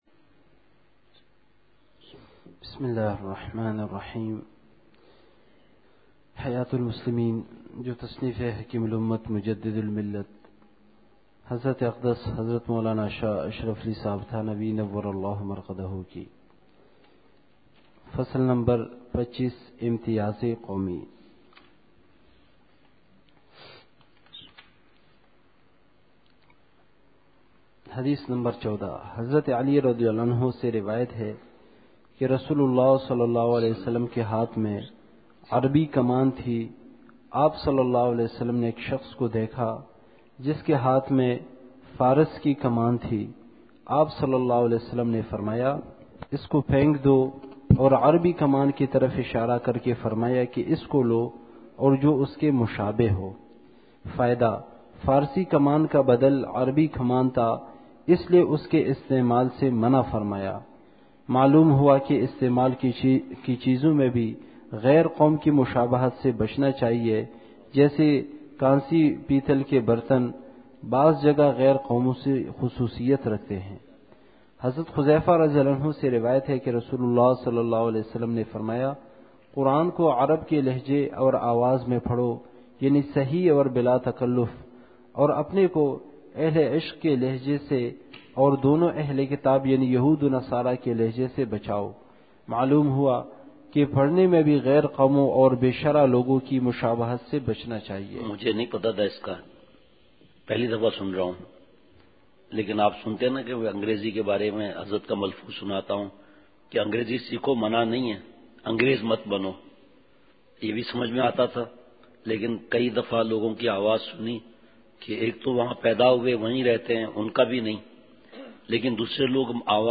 مجلس۰۴فروری ۲۰۱۹ء بعد عشاء : دین خشکی نہیں خوش خلقی سکھاتی ہے !